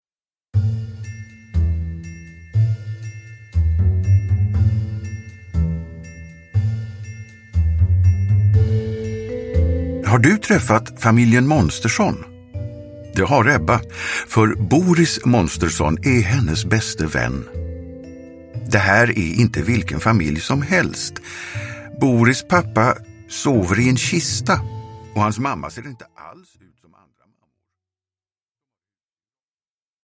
Monstermaskerad – Ljudbok – Laddas ner